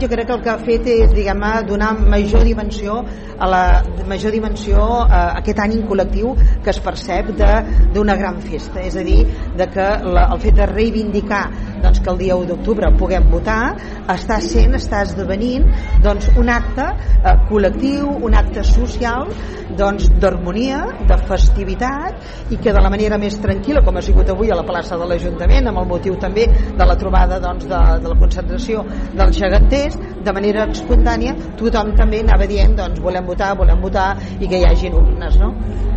L'alcaldessa de Calella, Montserrat Candini, durant el programa d'ahir de Radio Calella Televisió